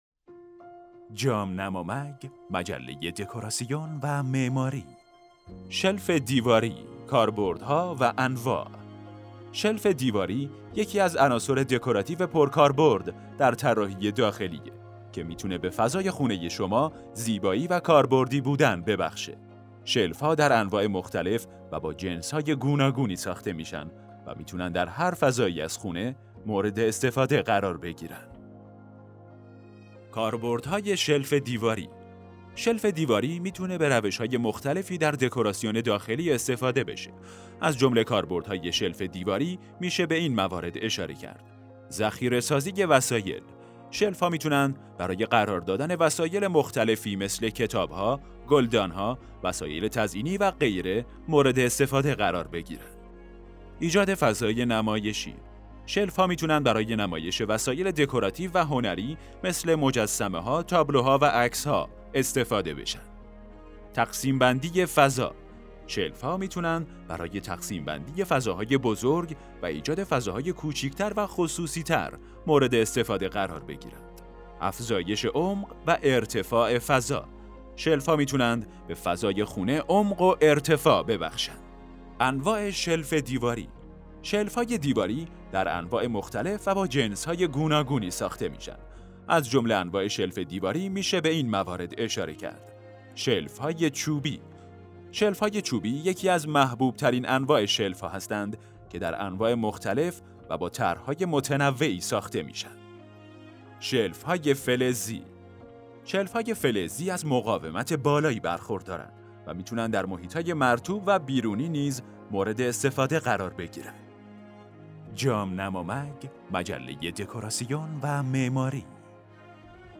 🎧نسخه صوتی مقاله ایده‌هایی برای استفاده از شلف دیواری در دکوراسیون داخلی